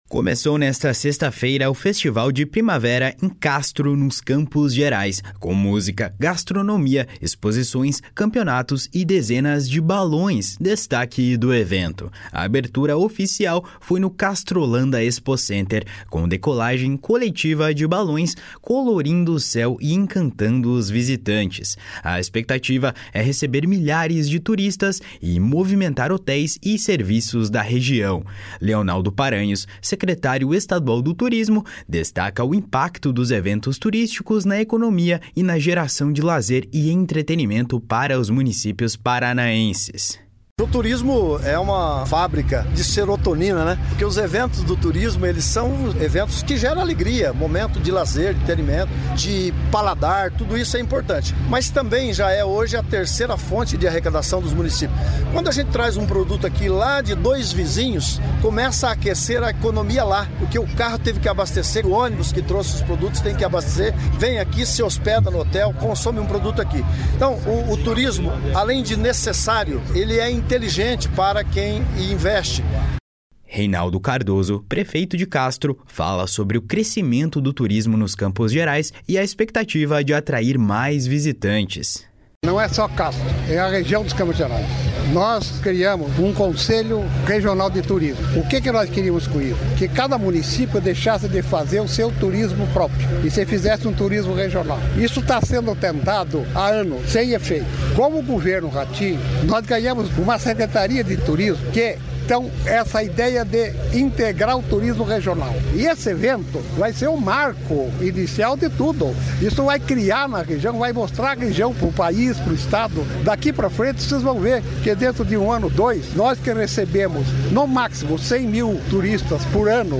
Leonaldo Paranhos, secretário estadual do Turismo, destaca o impacto dos eventos turísticos na economia e na geração de lazer e entretenimento para os municípios paranaenses. // SONORA LEONALDO PARANHOS //
Reinaldo Cardoso, prefeito de Castro, fala sobre o crescimento do turismo nos Campos Gerais e a expectativa de atrair mais de 1 milhão de visitantes por ano com ações integradas do Estado e municípios.